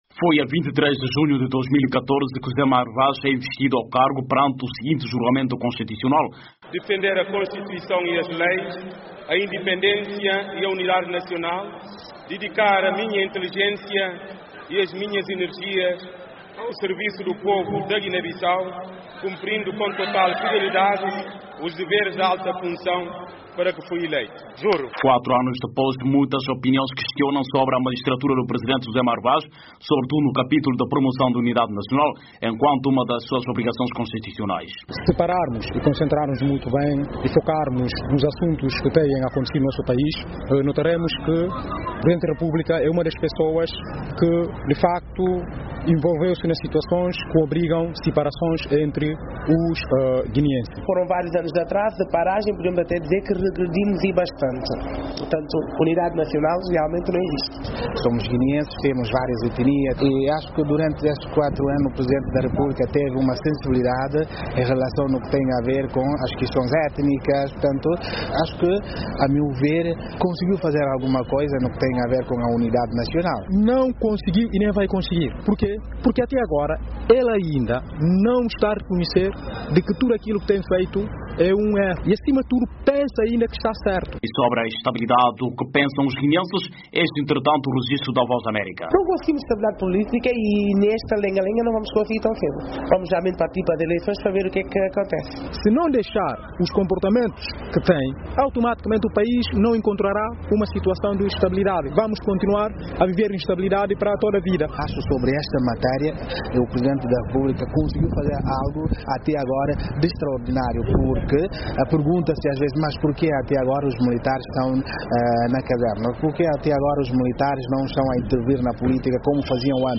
Os guineenses deixam a sua opinião, quando também se recorda esses quatro anos de mandato.